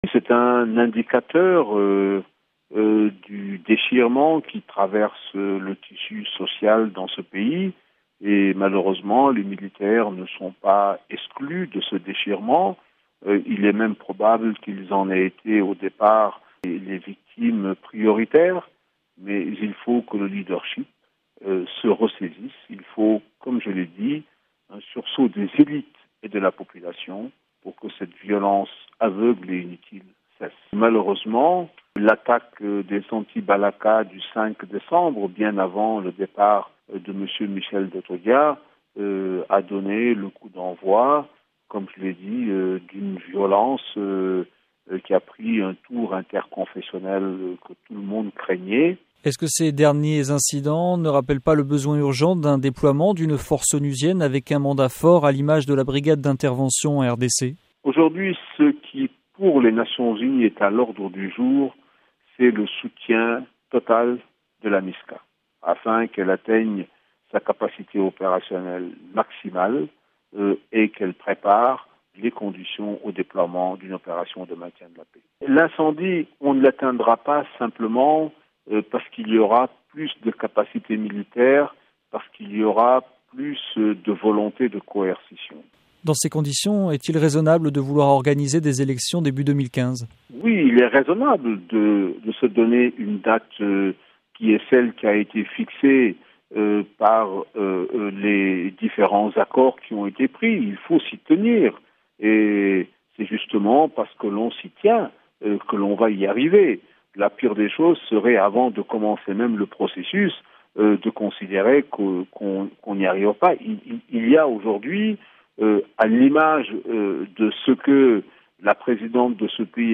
Babacar Gaye, représentant de l'ONU en Centrafrique